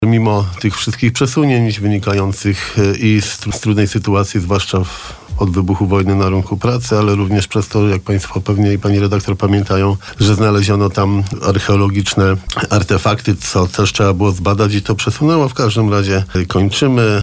Prezydent miasta w trakcie porannej rozmowy przypomniał, że koszt przebudowy Pl. Wojska Polskiego to 22 mln zł